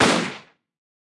Media:Shelly_base_atk_1.wav 攻击音效 atk 初级和经典及以上形态攻击音效